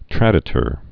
(trădĭ-tər)